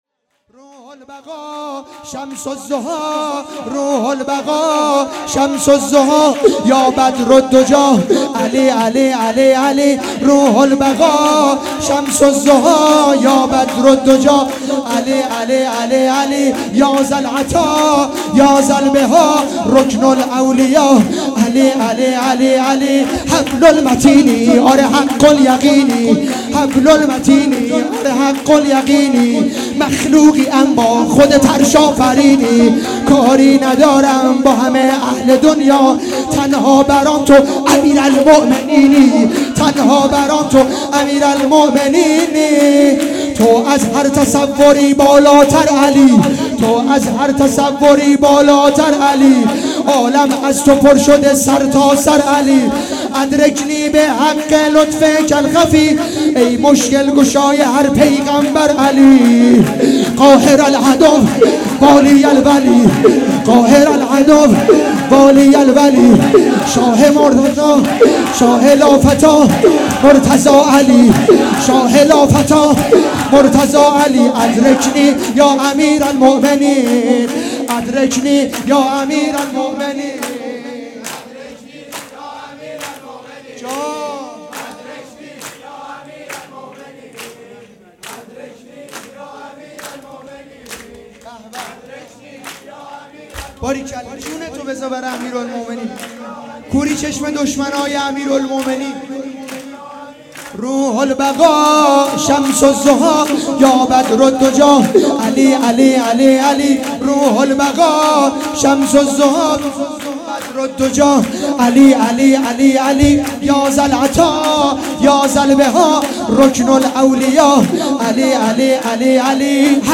جشن ولادت پیامبر اکرم و امام صادق علیه السلام